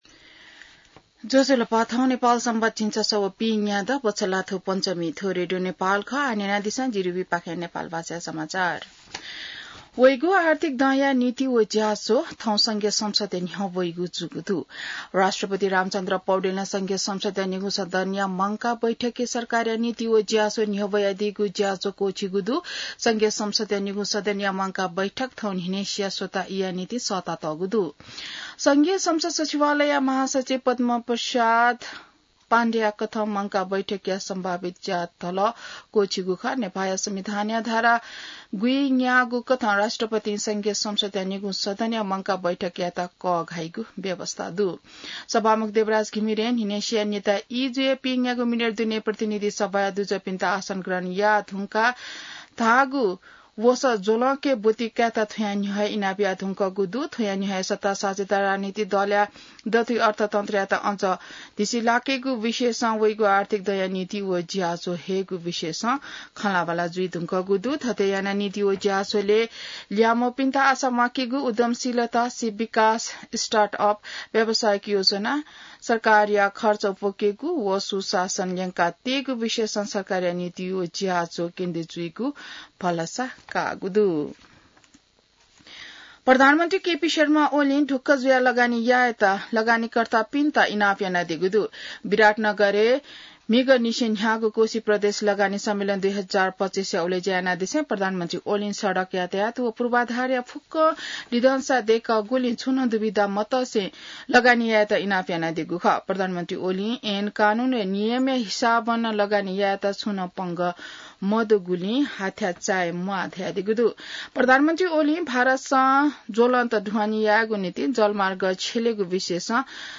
An online outlet of Nepal's national radio broadcaster
नेपाल भाषामा समाचार : १९ वैशाख , २०८२